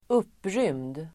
Ladda ner uttalet
Uttal: [²'up:rym:d]